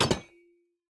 Minecraft Version Minecraft Version snapshot Latest Release | Latest Snapshot snapshot / assets / minecraft / sounds / block / copper_door / toggle2.ogg Compare With Compare With Latest Release | Latest Snapshot